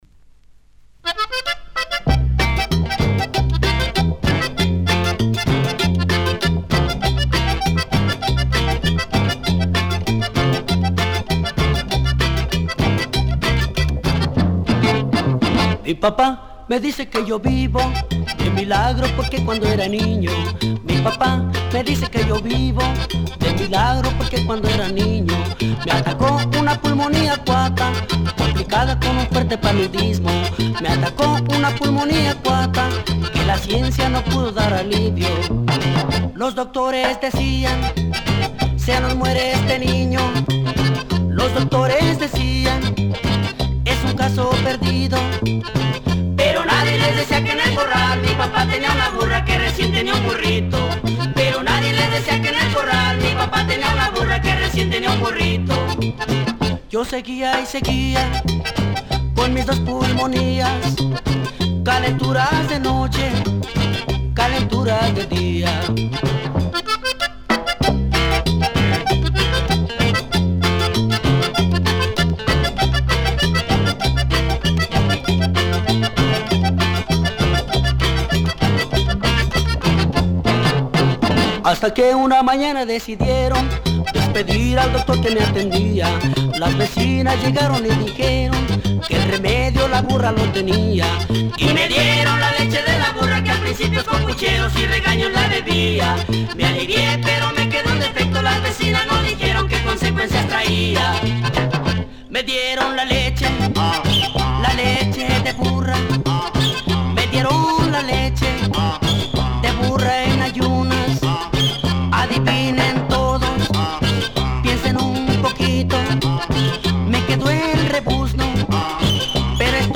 Published January 3, 2010 Garage/Rock 12 Comments
Even with their straight forward rock break-beat